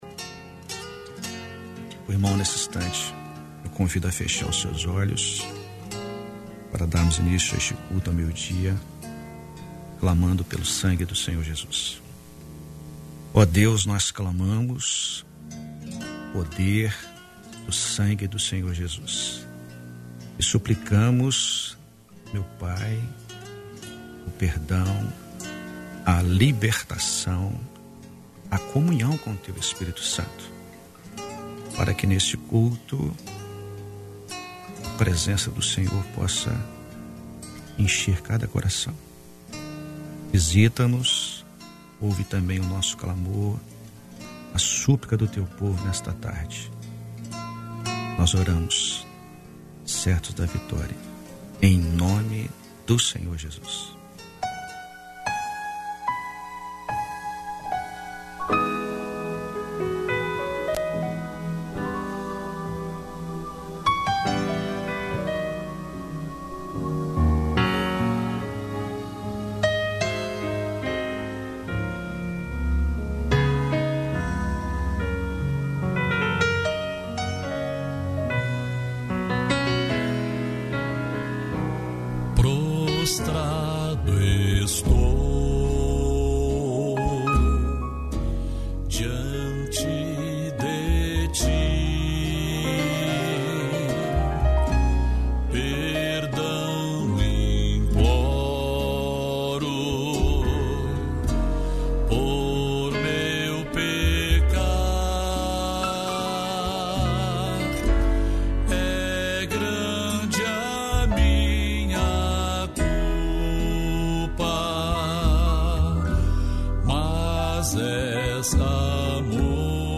Culto de oração da Igreja Cristã Maranata.